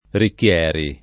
Ricchieri [ rikk L$ ri ] cogn.